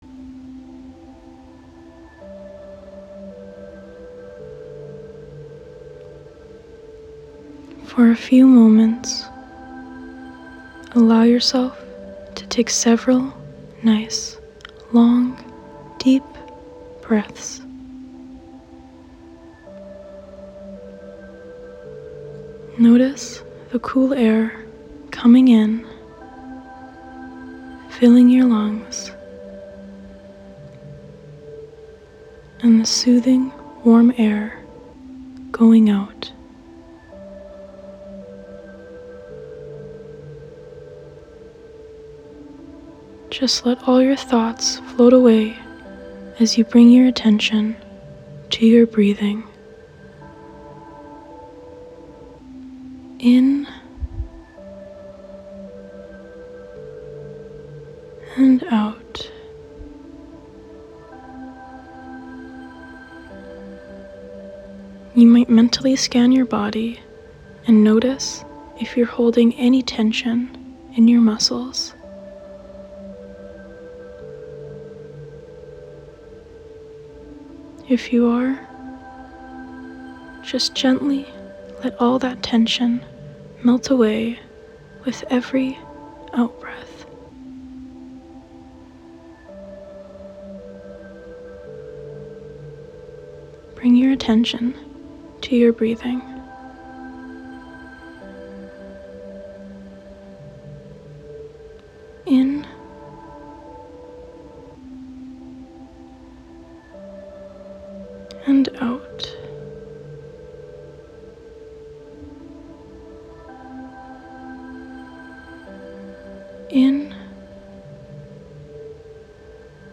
Beach Meditation 🧘‍♀
Beach Meditation.m4a